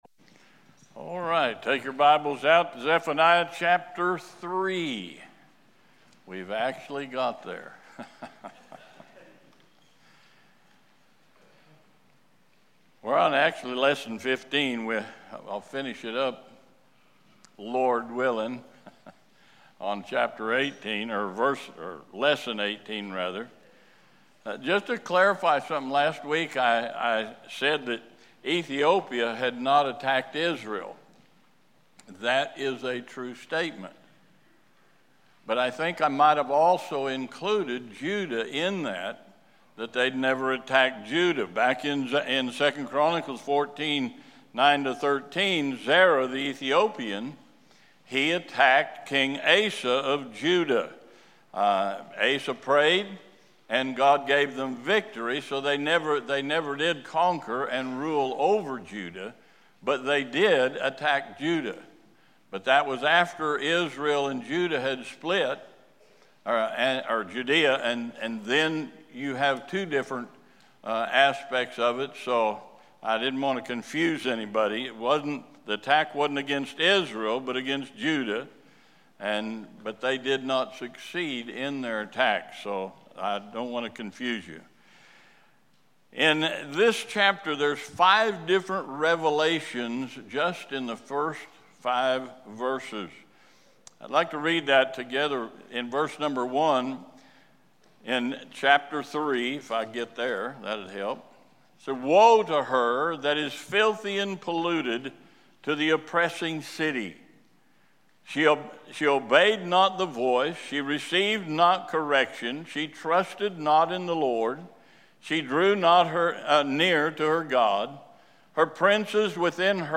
This sermon challenges you to use your God-given privileges to share the Gospel and honor Christ.